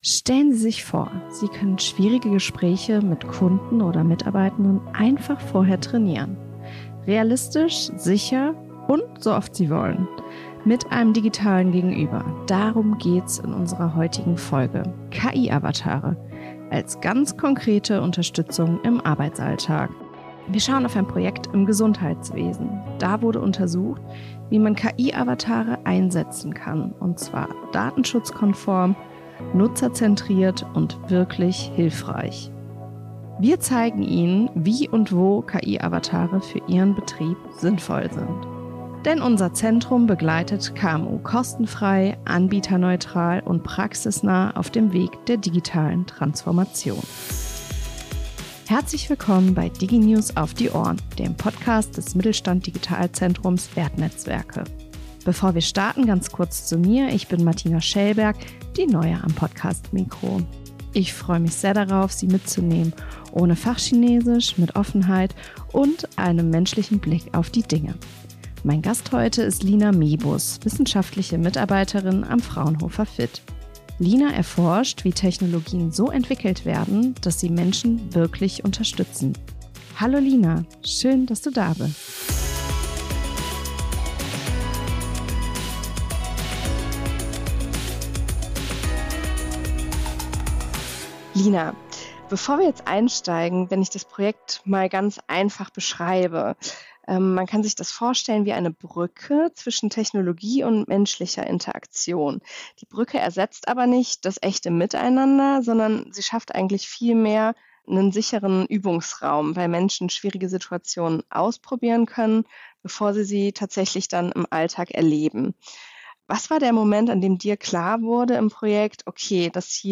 Gemeinsam sprechen wir darüber, wie digitale Avatare auf Basis von Künstlicher Intelligenz (KI) genutzt werden können, um Gespräche realistisch, sicher und wiederholbar zu trainieren – ohne Druck, aber mit echtem Lerngewinn.